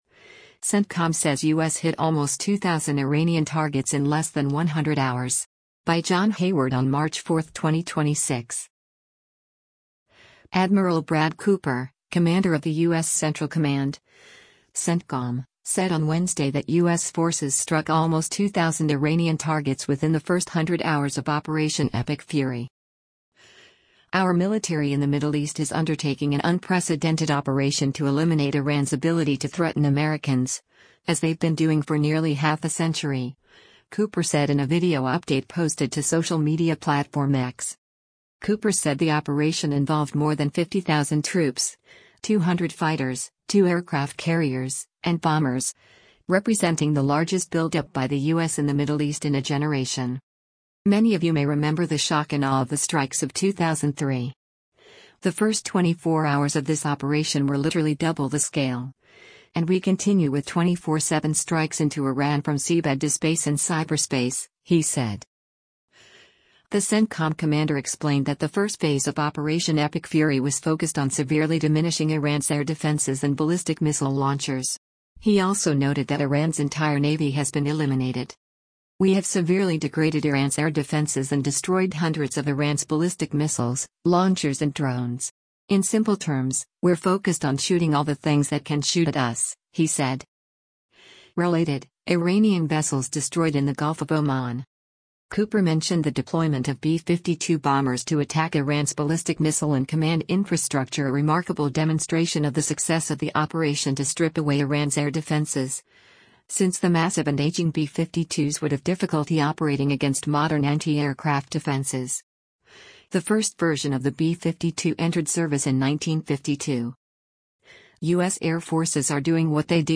“Our military in the Middle East is undertaking an unprecedented operation to eliminate Iran’s ability to threaten Americans, as they’ve been doing for nearly half a century,” Cooper said in a video update posted to social media platform X.